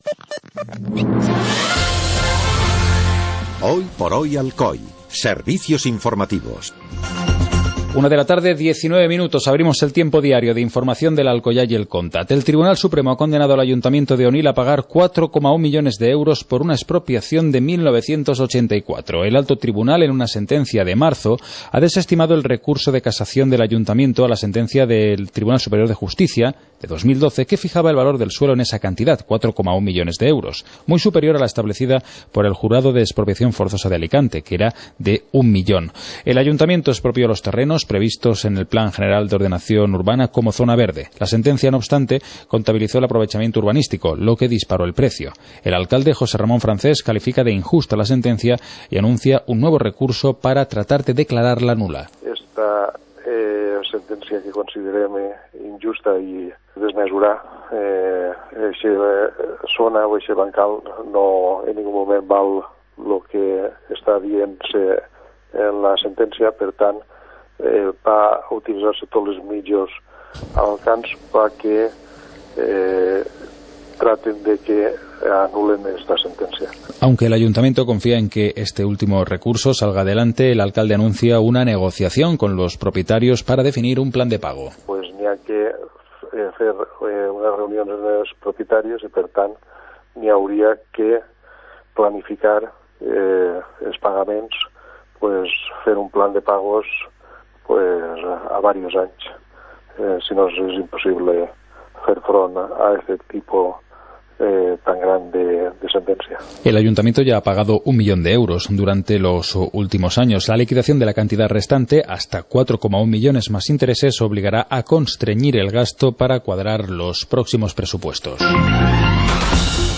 Informativo comarcal - viernes, 10 de abril de 2015